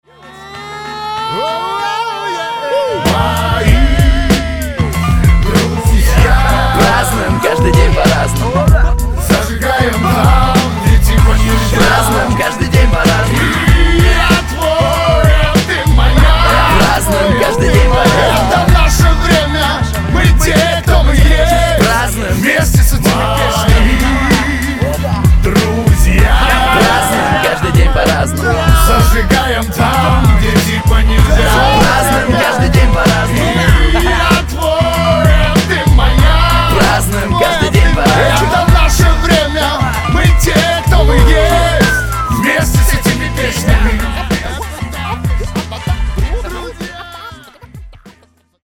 Хип-хоп
Rap